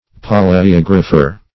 palaeographer - definition of palaeographer - synonyms, pronunciation, spelling from Free Dictionary Search Result for " palaeographer" : The Collaborative International Dictionary of English v.0.48: Palaeographer \Pa`l[ae]*og"ra*pher\, n., Same as Paleographer .
palaeographer.mp3